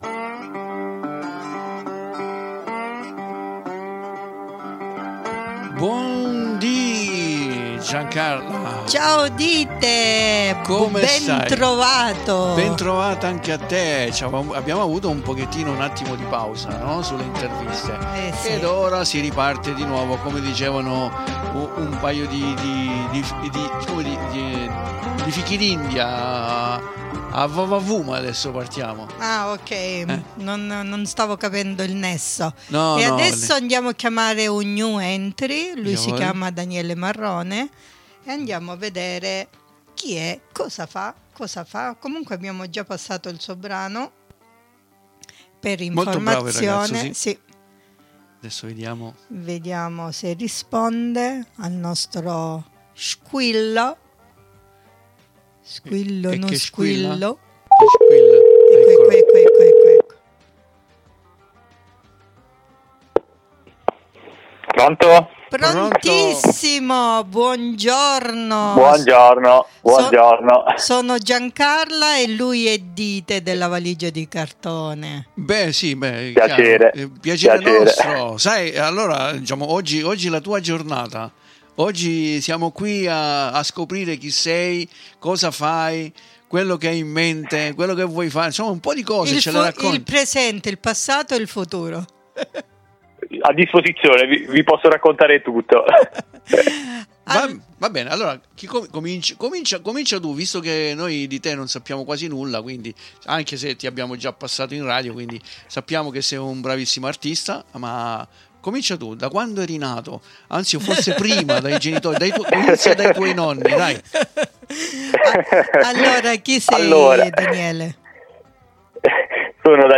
IL RESTO SCOPRITELO DA SOLI ASCOLTANDO L'INTERVISTA CONDIVISA QUI IN DESCRIZIONE.